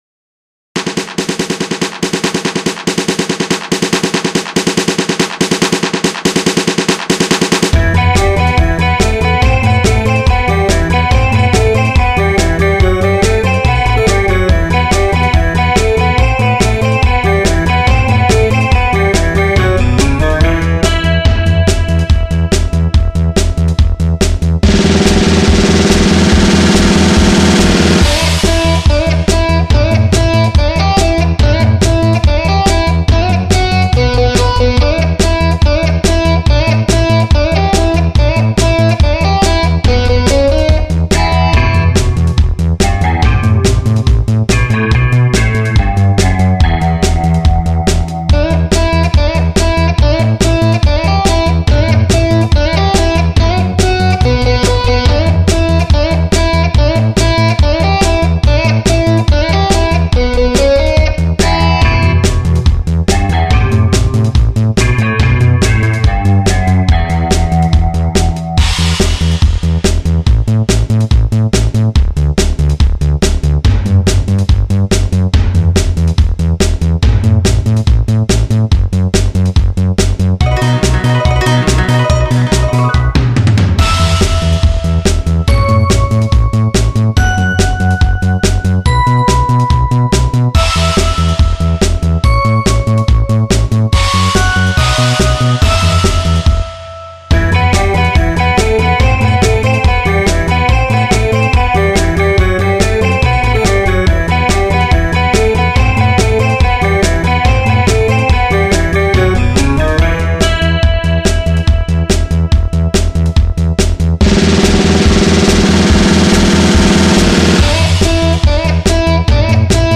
Drum/Bass/FX/E.Guitar